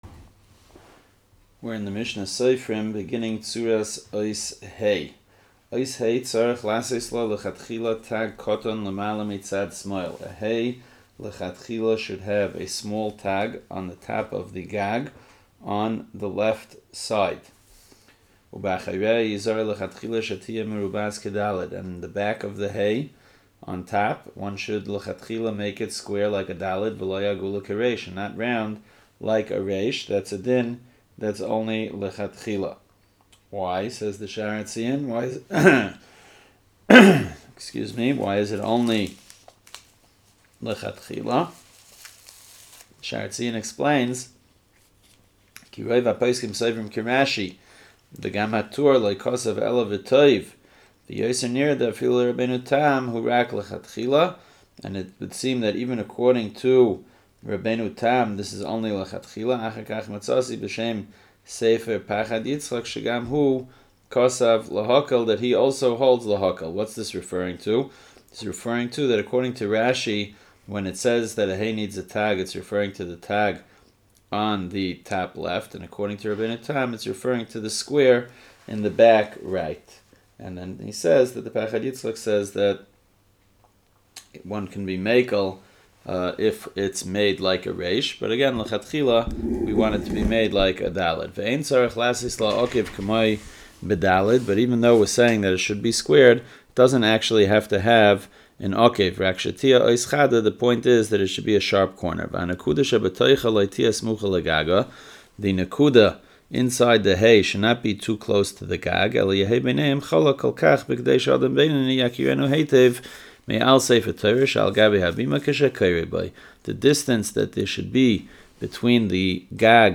Lesson 3 – Hey and Vav